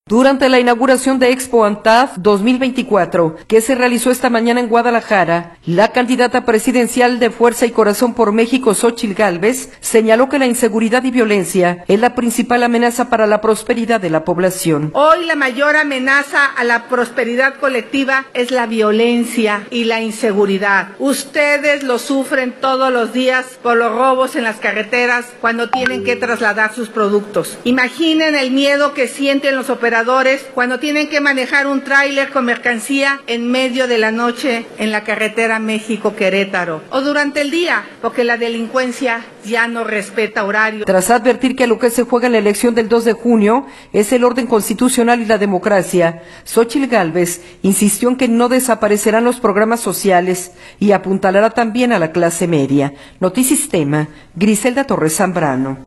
Durante la inauguración de Expo Antad 2024 que se realizó esta mañana en Guadalajara, la candidata presidencial Fuerza y Corazón por México, Xóchitl Gálvez, señaló que la inseguridad y violencia es la principal amenaza para la prosperidad de la población.